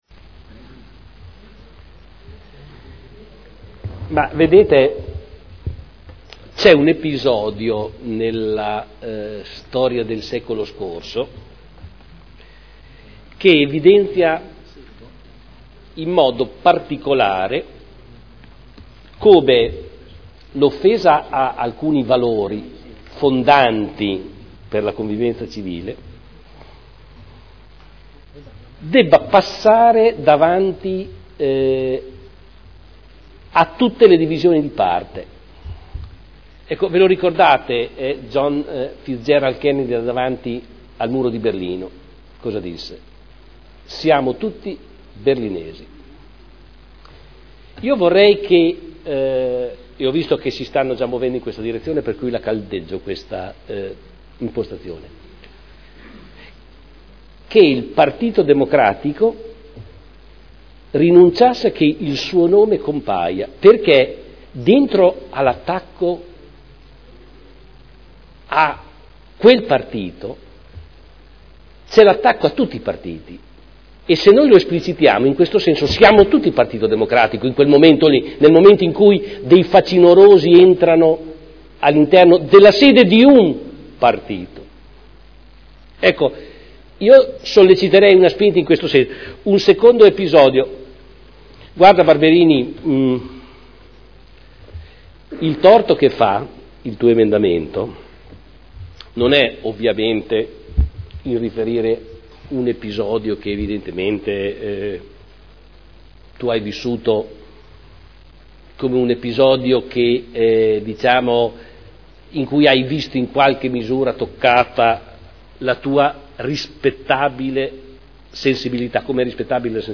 Giorgio Pighi — Sito Audio Consiglio Comunale
Seduta del 30/05/2011.